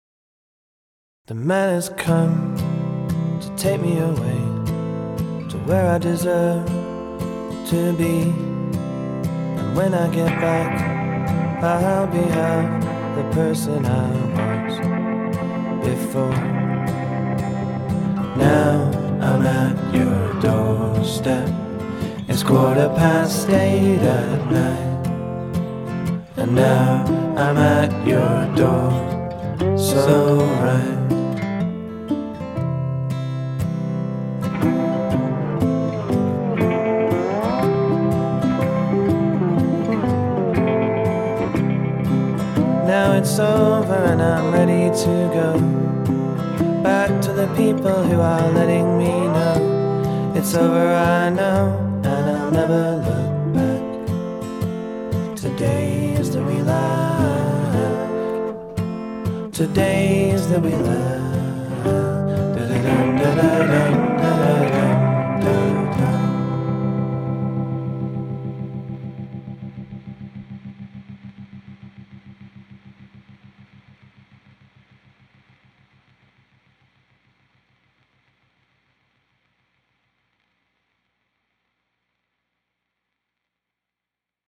here is an unmastered track off my new record.